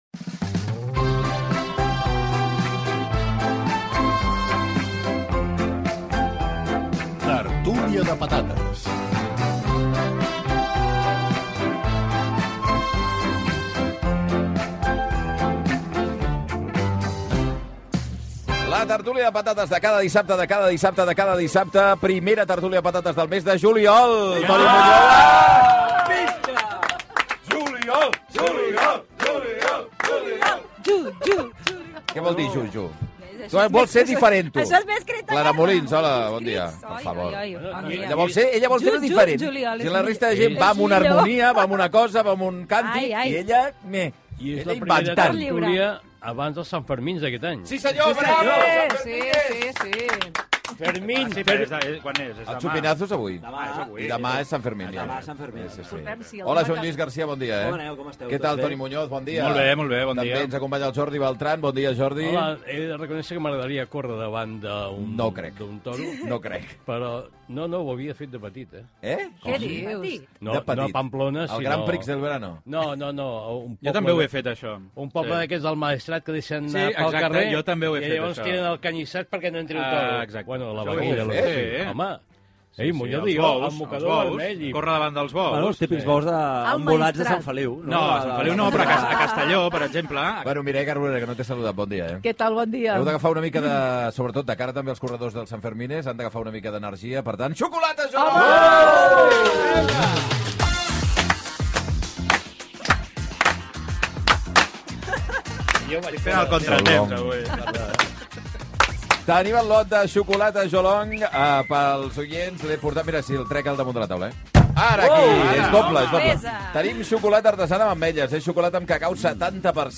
Espai "Tertúlia de patates", presentació dels integrants de la tertúlia, publicitat i concurs, allò que ha sorprès a cadascun dels integrants de la tertúlia